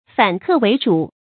反客為主 注音： ㄈㄢˇ ㄎㄜˋ ㄨㄟˊ ㄓㄨˇ 讀音讀法： 意思解釋： 客人反過來成為主人。